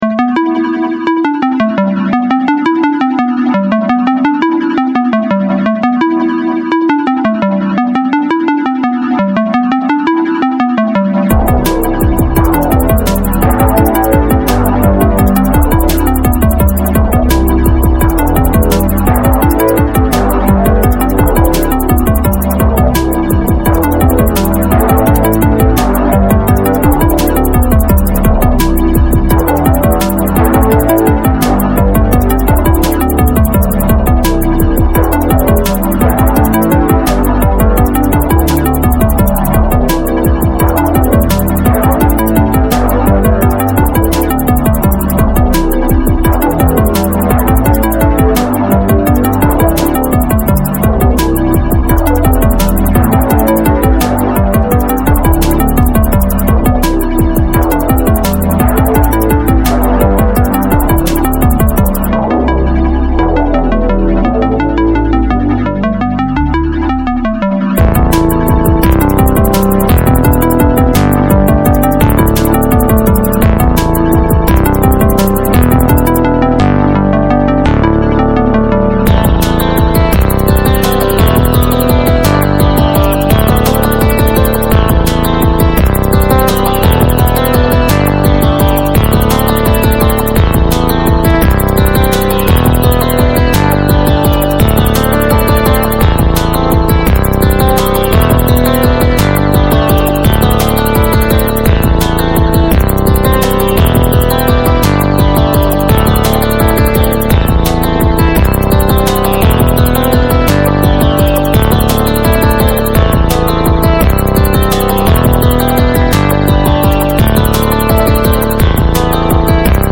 It’s the snare and hi-hats, plus soft kicks filling in the blanks/rumble between the harder kick notes, which is a sample of the DFAM, back when I had one.
The maracas is a sample.
The thru track with the ND3P goes into a lo-fi fx on the Octatrack so the bit rate is lower on that (thru) track compared to the rest of the mix.